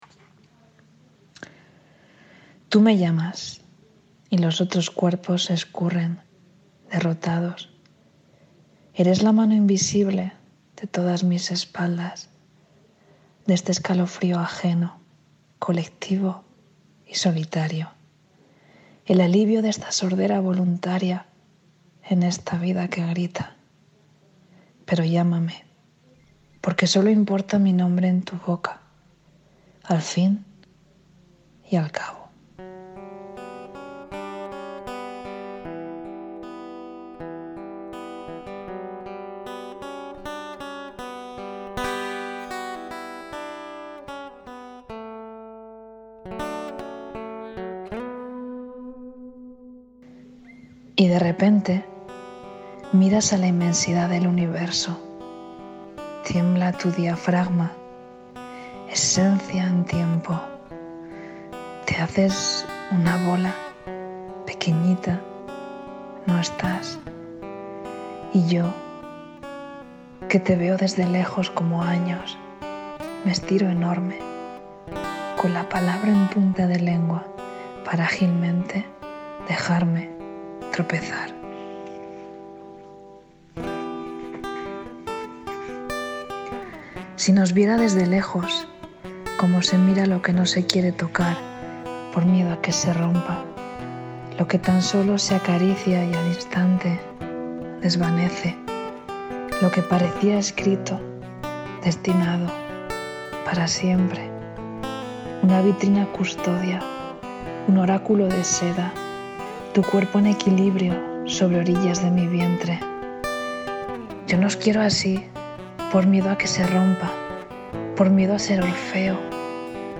Llana de adentro, Burgos, 19:00.
una atmósfera íntima, cercana y envolvente
desde una óptica más electrónica